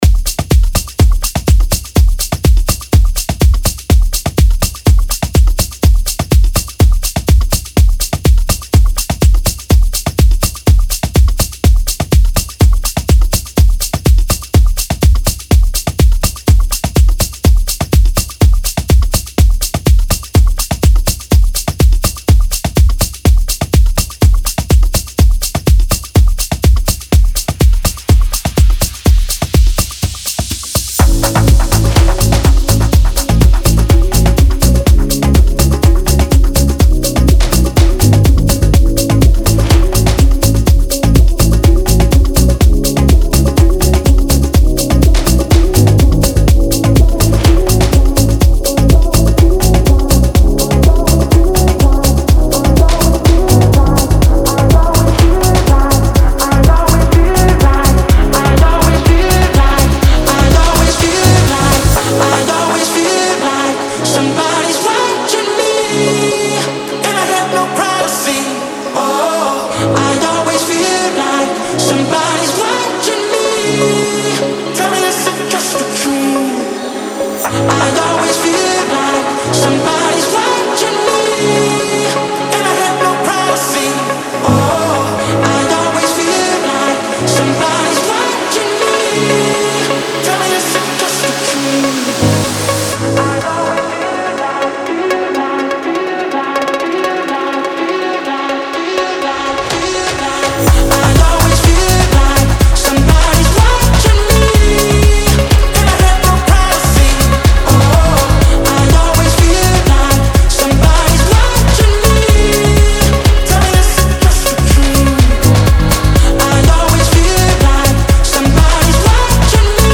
• Жанр: Electronic, Dance, House